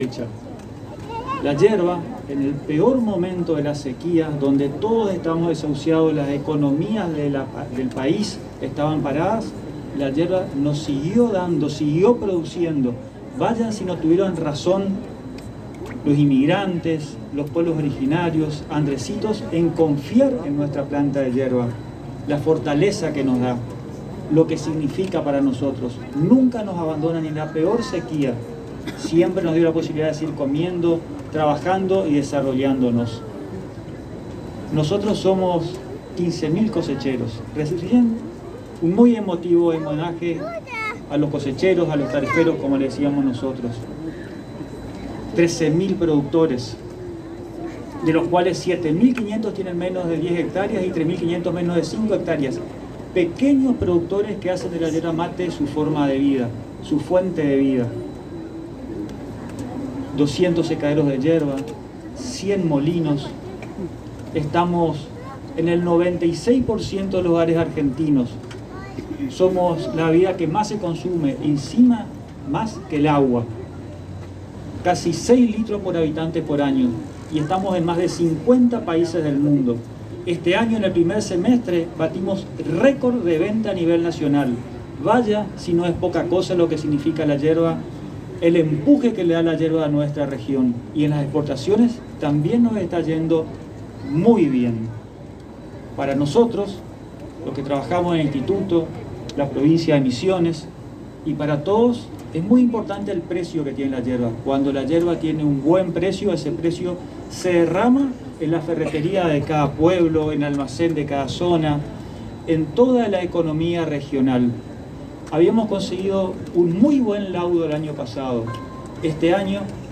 El Presidente del Instituto Nacional de la Yerba Mate Juan José Szychowski en el acto inaugural de la 45 Fiesta Nacional e Internacional de la Yerba Mate rescató el empuje de mujer misionera de la Intendente María Eugenia Safrán que tuvo el coraje de impulsar cada fiesta de la Yerba Mate a pesar de la sequía o de los tiempos difíciles nunca dejó caer la celebración por el producto madre de la región.